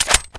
ump45_clipout.mp3